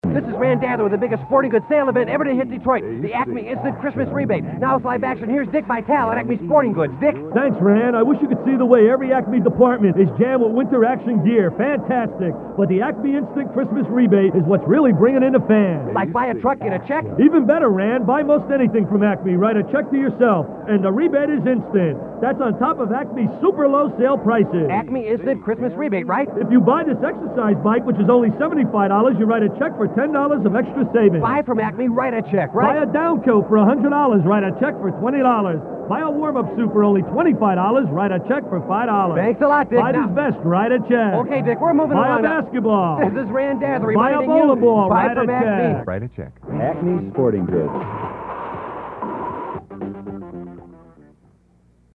On November 19, 1979, just days after he’d been fired as Detroit Pistons head coach, we got Dick (“awesome, baby!”) Vitale to cut this silly spot for Acme Sporting Goods.